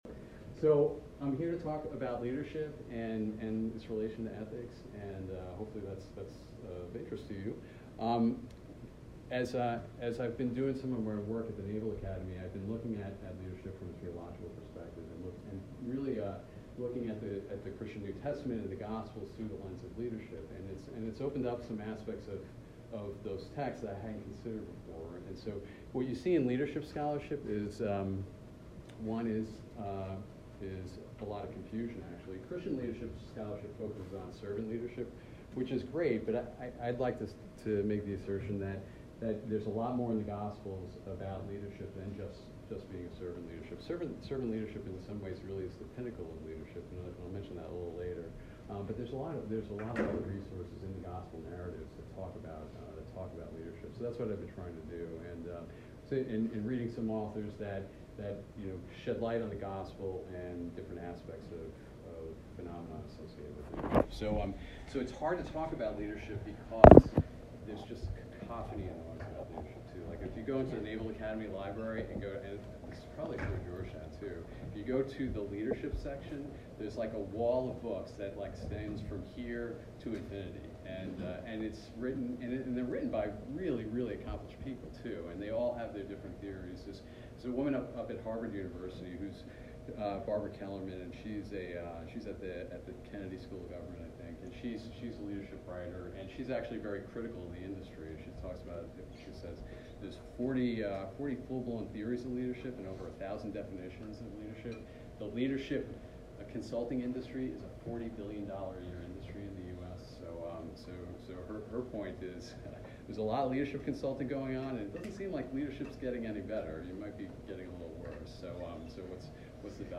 This lecture was given at Georgetown University on November 19, 2019.